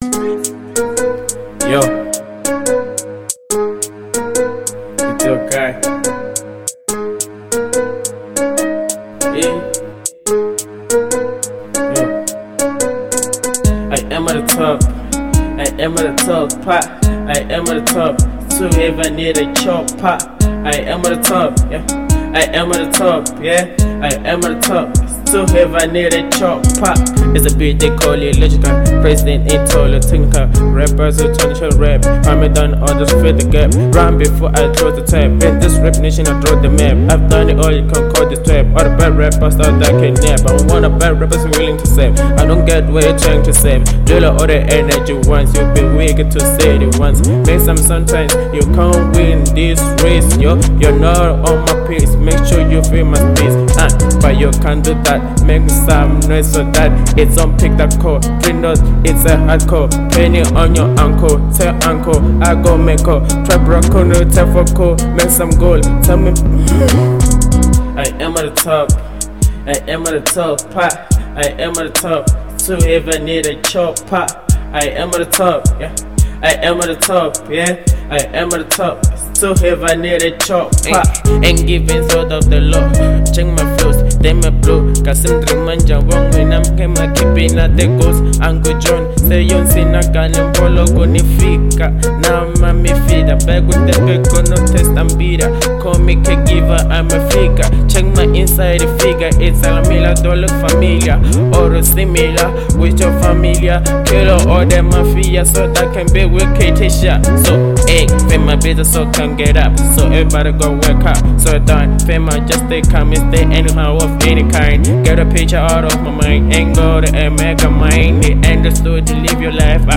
02:38 Genre : Trap Size